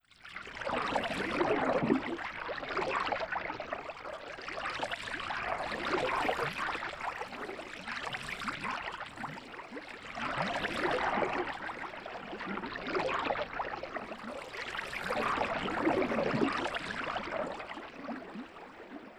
River.wav